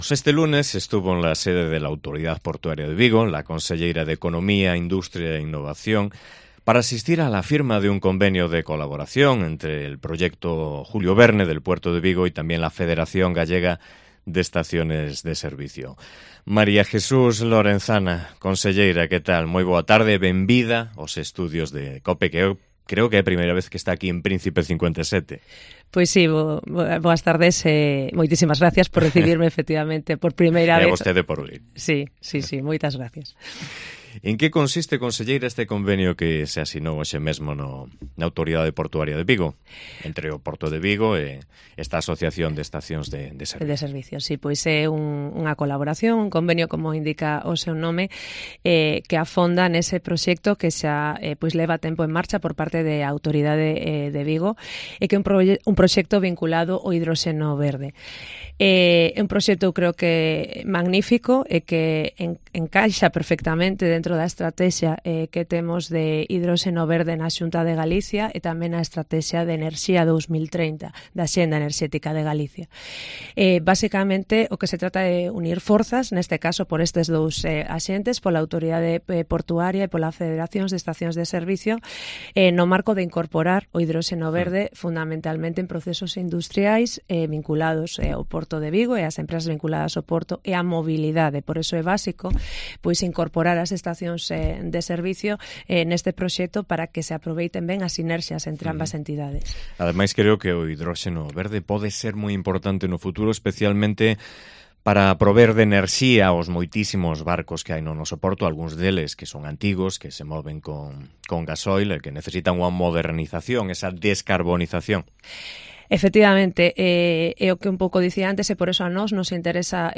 Entrevista coa conselleira María Jesús Lorenzana